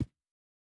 Shoe Step Stone Hard B.wav